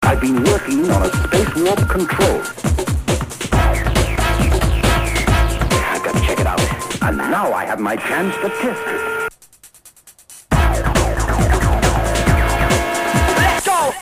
breakbeat track